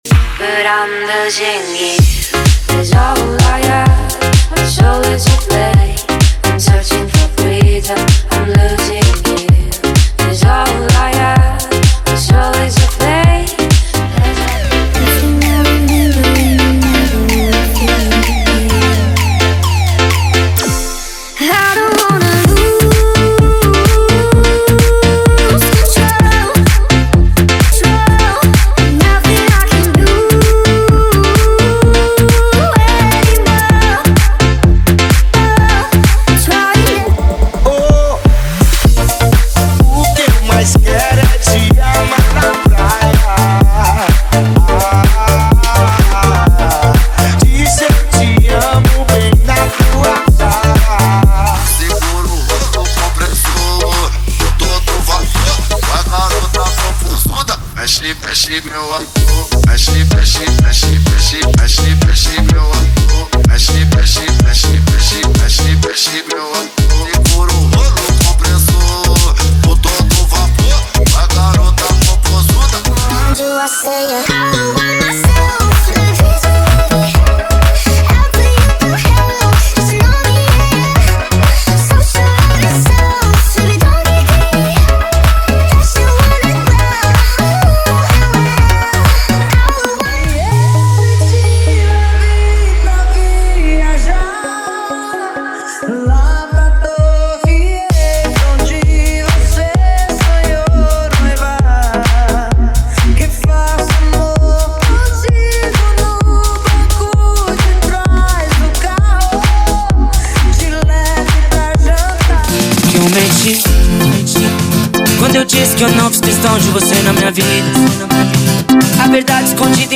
Os Melhores Dance Comercial do momento estão aqui!!!
• Sem Vinhetas
• Em Alta Qualidade